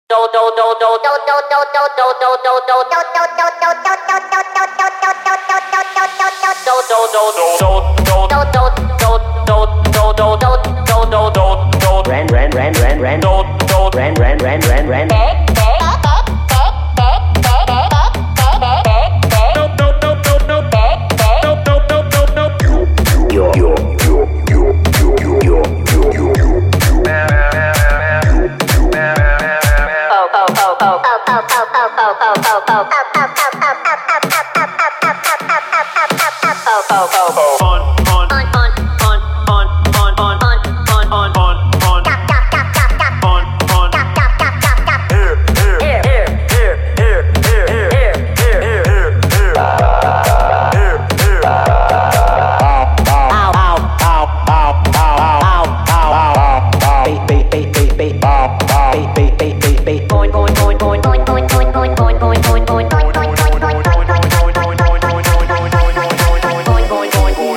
(COVER)